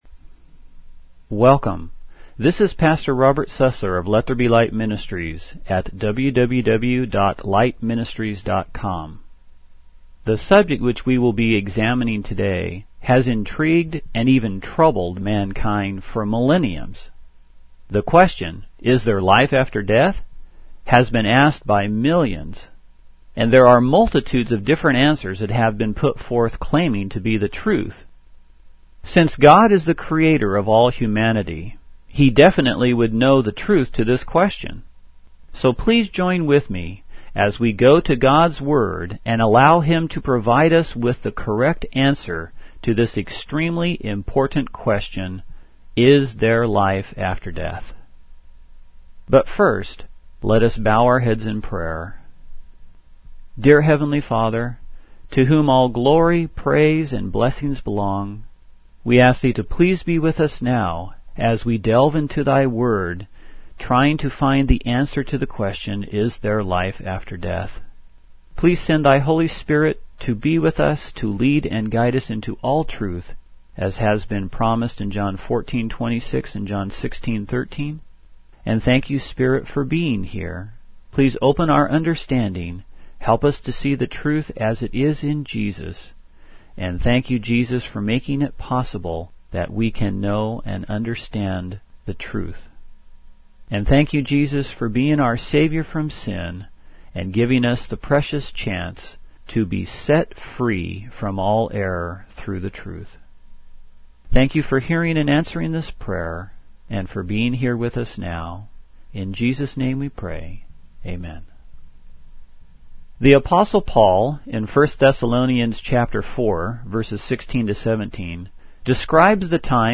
Audio Sermons - No Summary